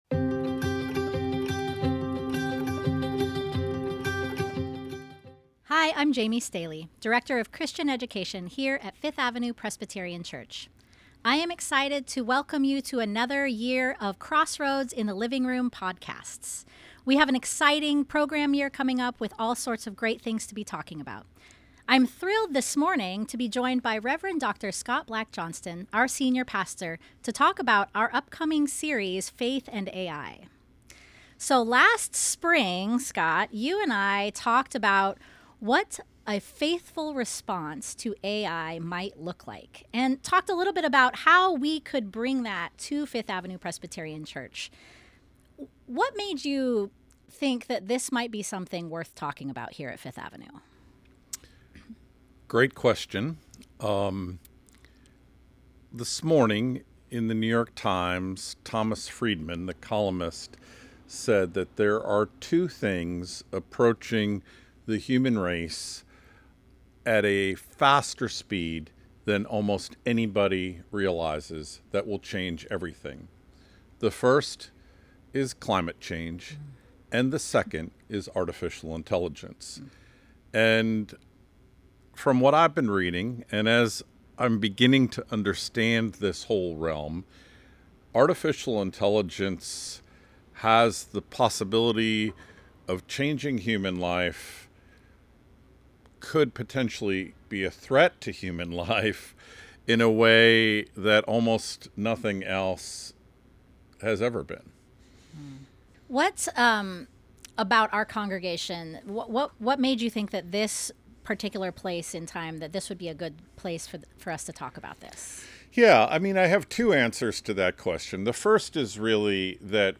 This conversation kicks off our Faith and AI series, which begins with the Gotto Lecture featuring Dr. Shannon Vallor on September 28.